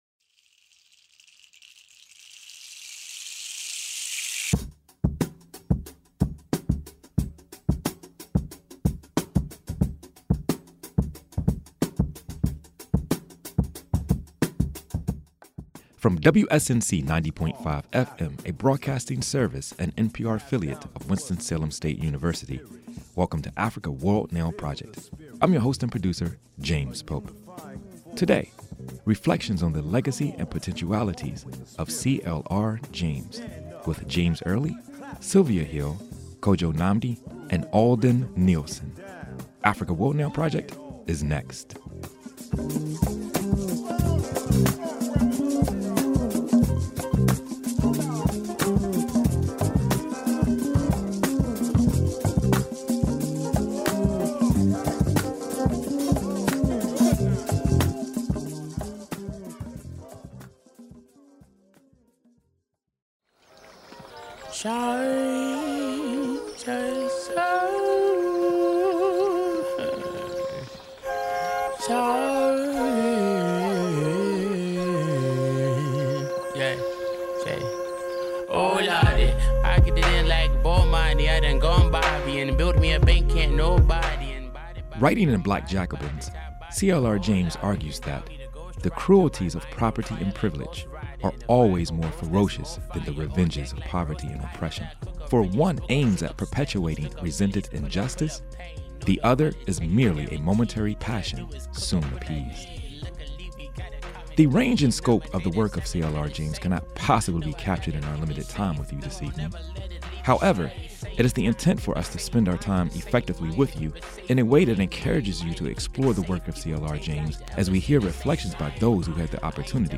interviews with and presentations from artists, activists, scholars, thinkers, practitioners, and other stake holders throughout the Africana world.